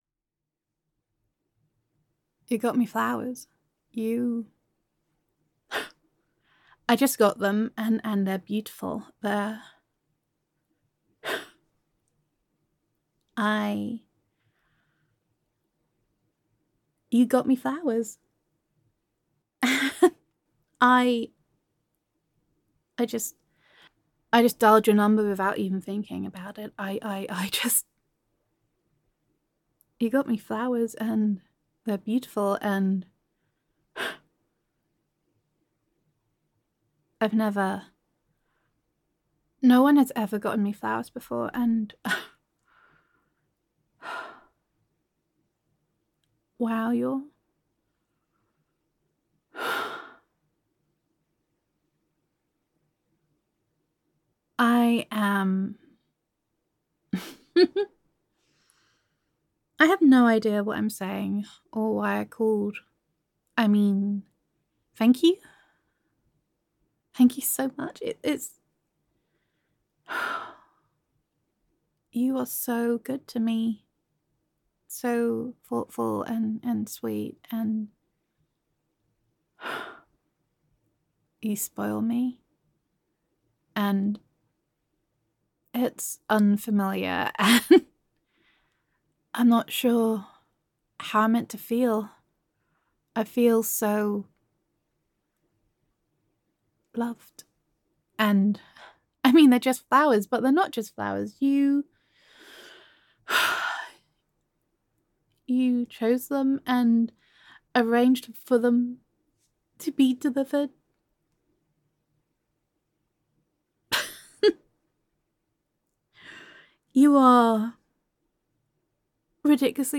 [F4A] You Got Me Flowers [Adoring][Feeling Loved][Gender Neutral][Emotional Overwhelmed Girlfriend Voicemail]